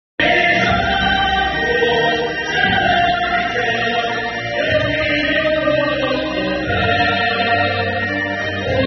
“劳动颂歌献给党”专场总结展示彩排进行时，大家正在紧张有序排练，节目一定很精彩，更有精彩花絮提前剧透。